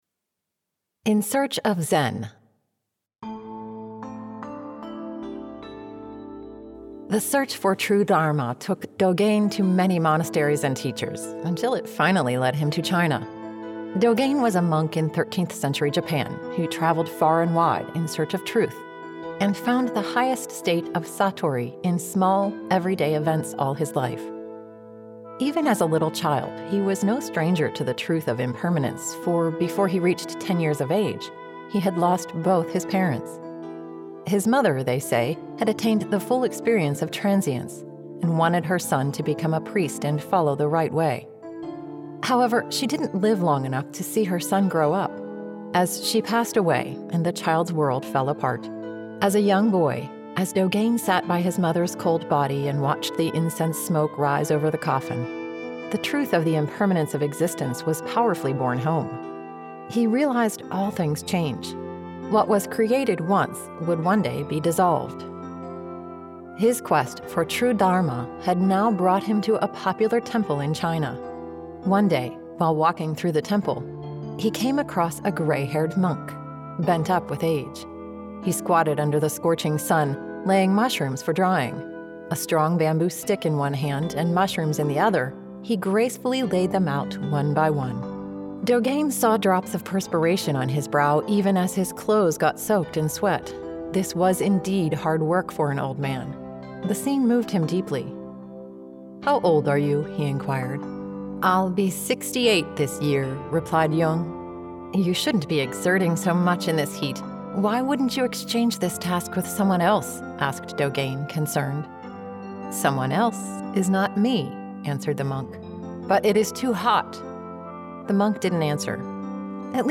In Search of Zen (Audio Story)